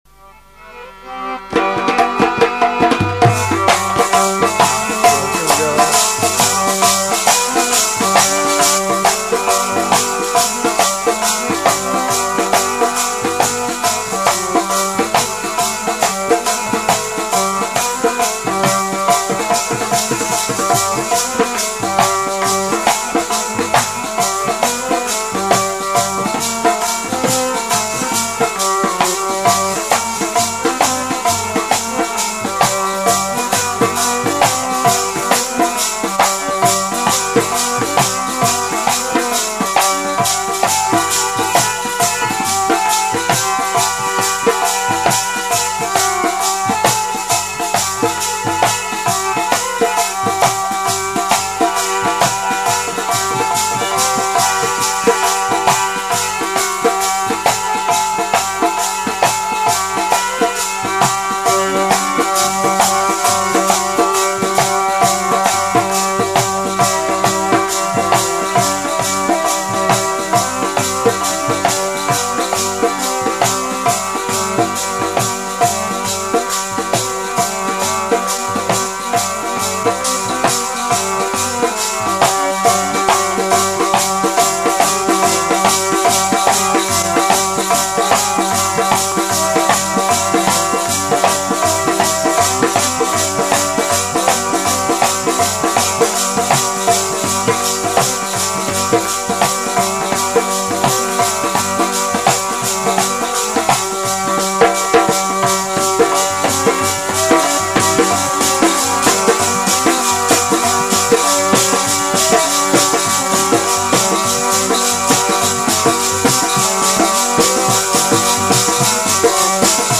Album:Gurbani Da Satkar Genre: Gurmat Vichar